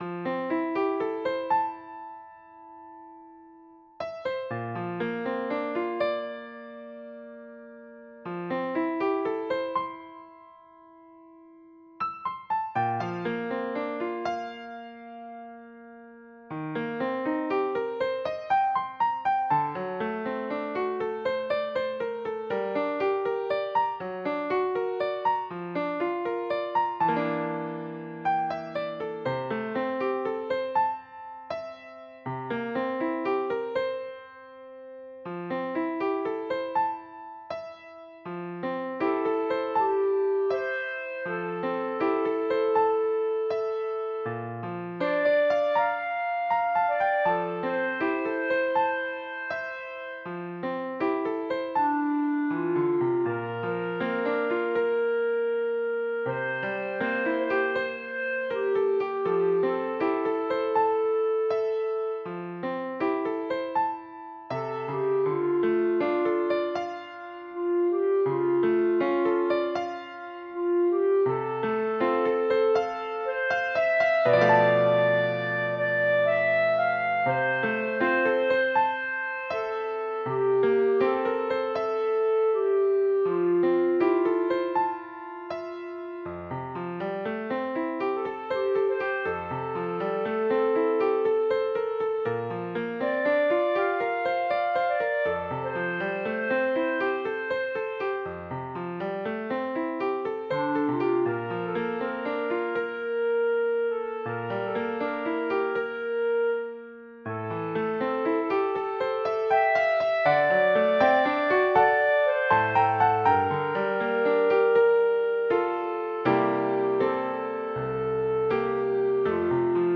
Solo voice and piano
世俗音樂